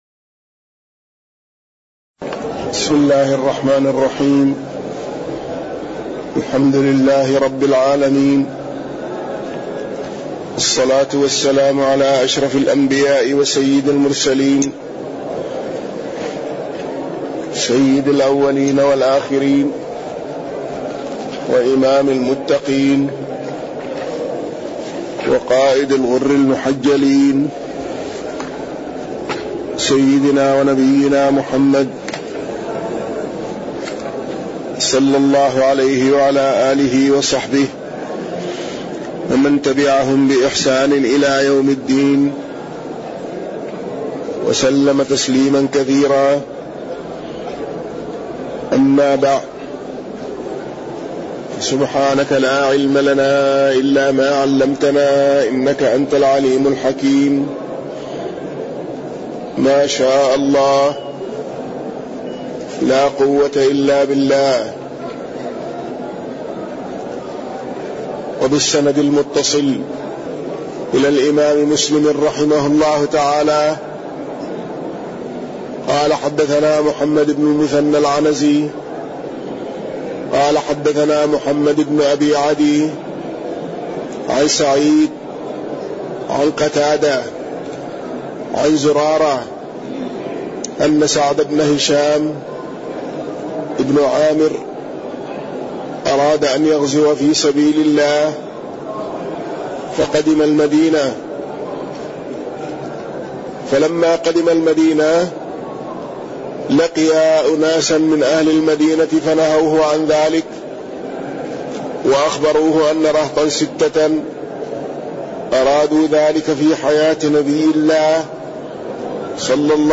تاريخ النشر ٢٩ شوال ١٤٣٠ هـ المكان: المسجد النبوي الشيخ